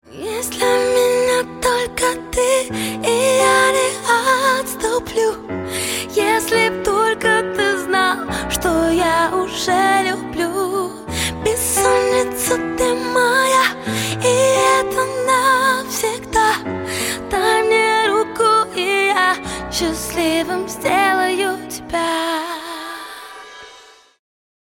• Качество: 128, Stereo
женский вокал
красивая мелодия
медленные
романтичные
красивый женский голос